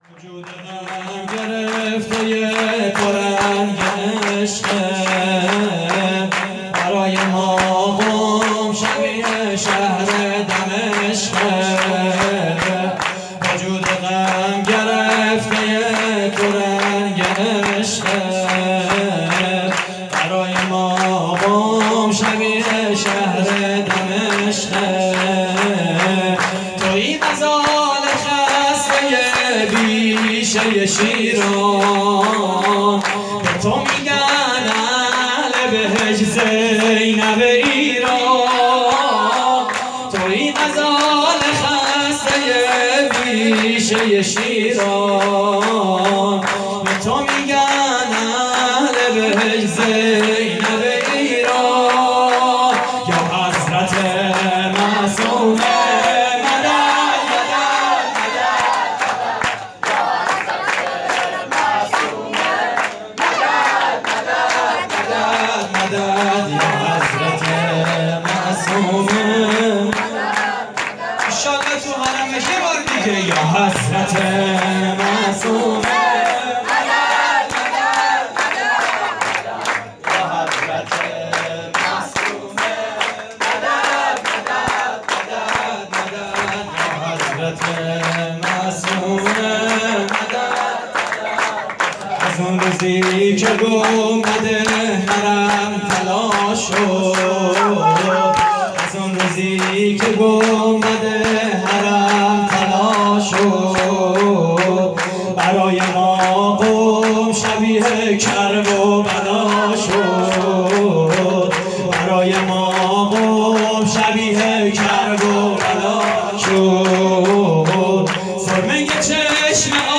شور: زینب ایران
مراسم جشن ولادت حضرت معصومه (س) / هیئت کانون دانش‌آموزی شهید علم الهدی